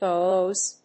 /boz(米国英語), bəʊz(英国英語)/